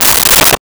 Toilet Paper Dispenser 01
Toilet Paper Dispenser 01.wav